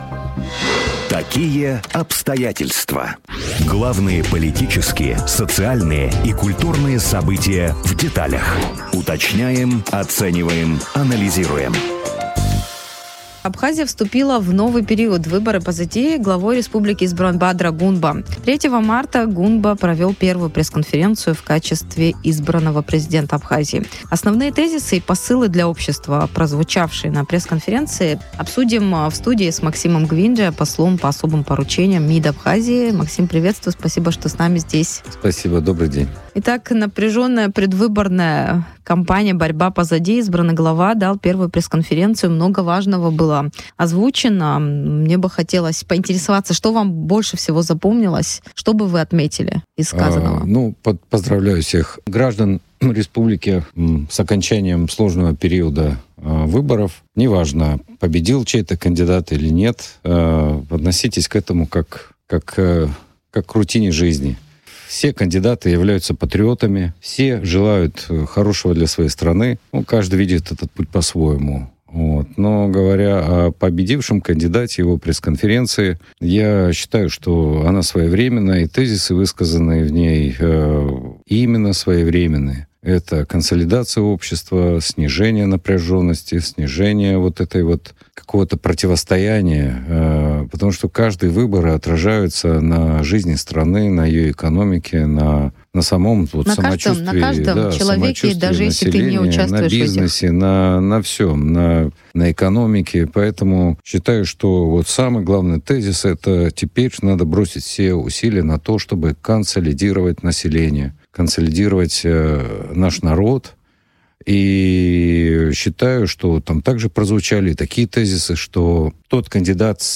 Основные тезисы и задачи, прозвучавшие на пресс-конференции избранного президента Абхазии, в эфире радио Sputnik обсудили с послом по особым поручениям МИД Абхазии Максимом Гвинджия.